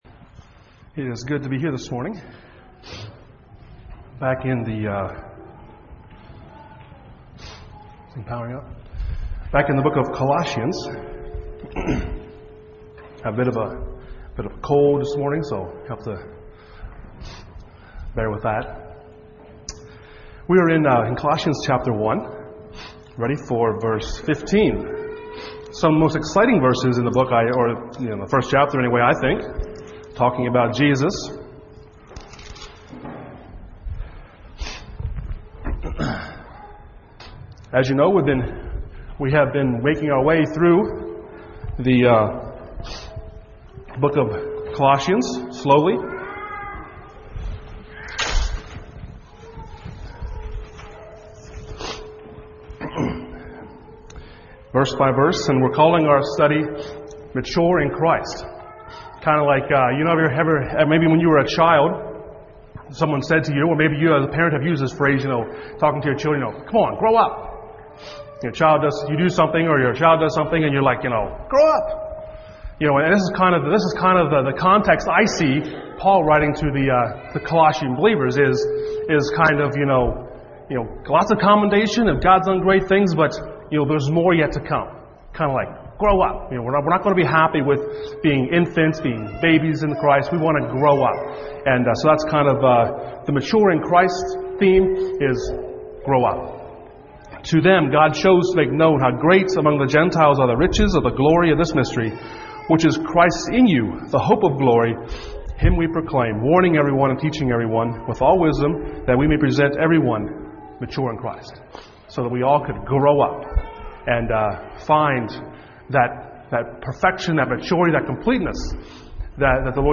Sunday Morning Studies in Colossians Passage: Colossians 1:15-20 Service Type: Sunday Morning %todo_render% « Prayer for Colossae